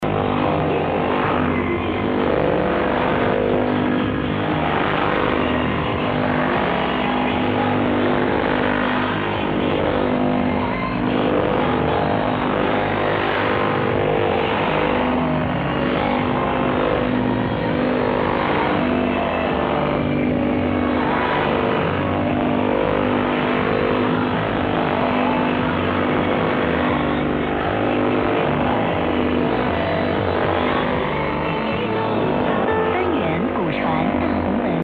9920 кГц - станция подавления работает. Качающаяся несущая. Хорошо принимается в SSB.
Подавление работало поверх Sound of Hope.
9920_khz_am_soh_jamm.mp3